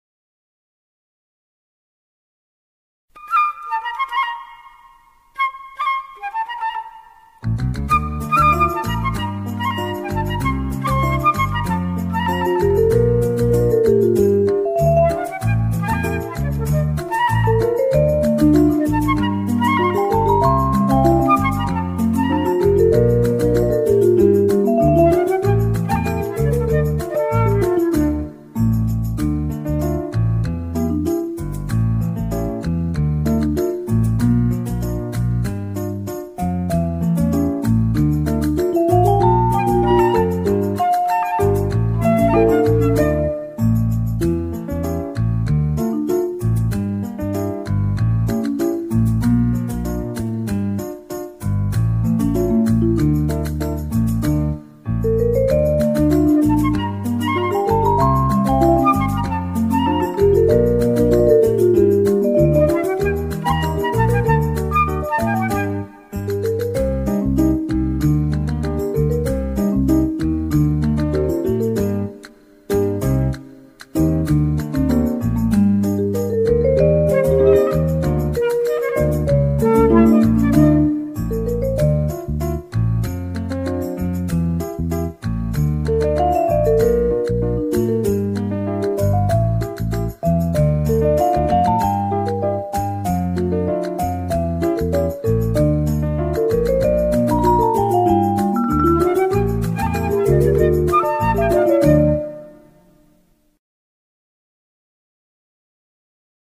سرود های کودک
بی‌کلام